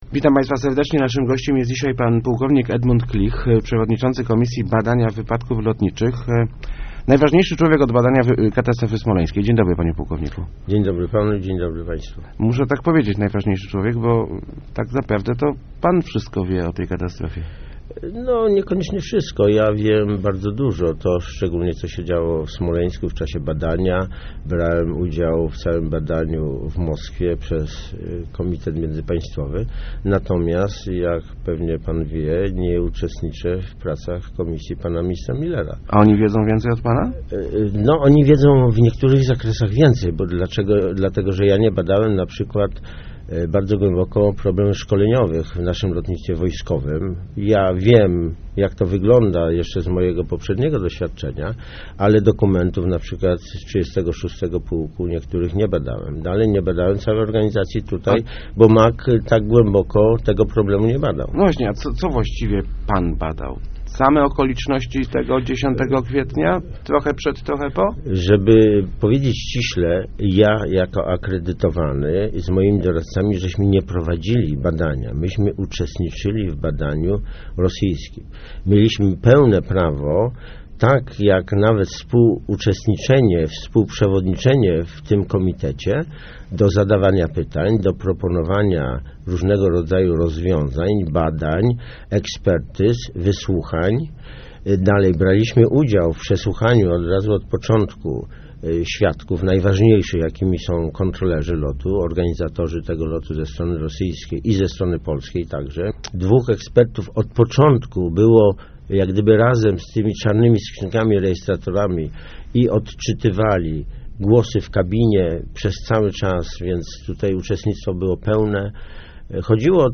Rosjanie nie grali fair - mówił w Rozmowach Elki płk Edmund Klich, akredytowany do komisji MAK badającej katastrofę smoleńską. Przyznał on, że ukrywano przed nim dokumenty świadczące o winie rosyjskich kontrolerów lotu.